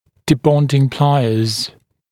[dɪ’bɔndɪŋ ‘plaɪəz][ди’бондин ‘плайэз]щипцы для снятия брекетов